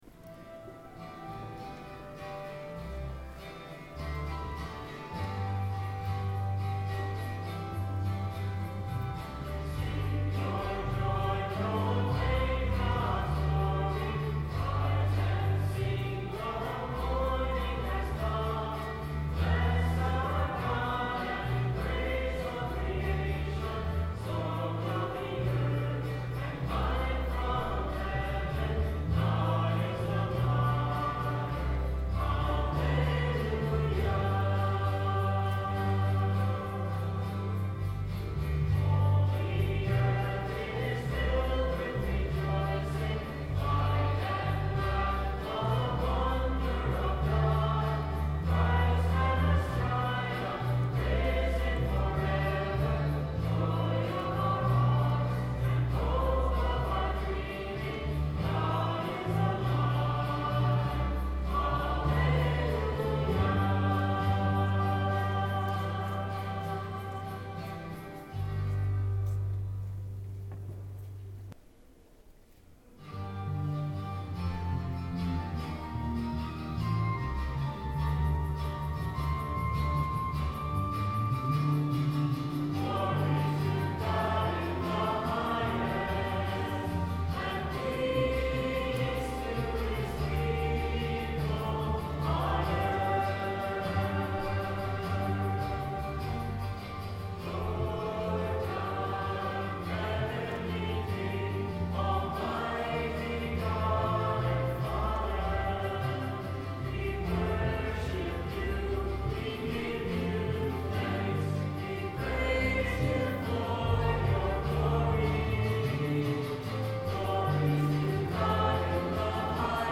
10/18/09 10:30 Mass Recording of Music - BK1030
Note that all spoken parts of the Mass have been removed from this sequence.